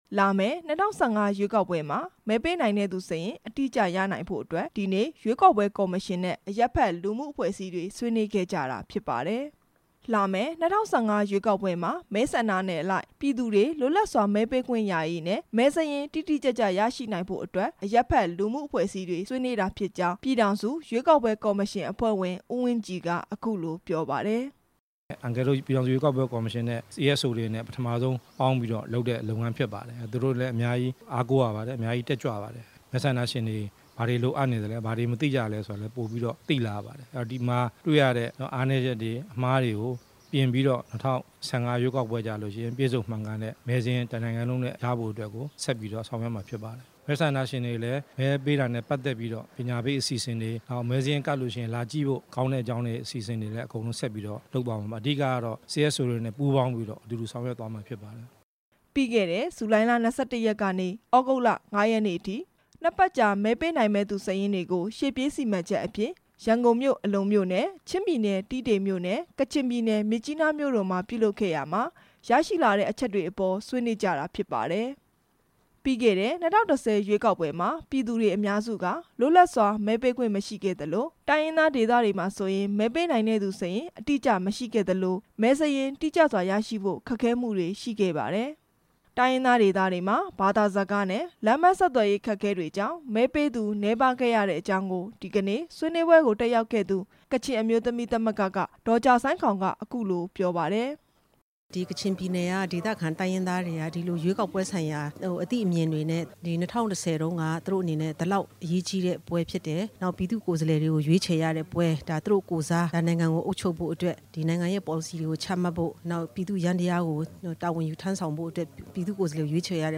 ရန်ကုန်မြို့ မီကာဆာဟိုတယ်မှာကျင်းပတဲ့ ဆွေးနွေးပွဲ မှာ  အရပ်ဘက်လူမှုအဖွဲ့အစည်းတွေနဲ့ တွေ့ဆုံဆွေးနွေး ရတဲ့ ရည်ရွယ်ချက်ကို ပြည်ထောင်စုရွေးကောက် ပွဲကော်မရှင်အဖွဲ့ဝင် ဦးဝင်းကြည်က အခုလို ပြော ပါတယ်။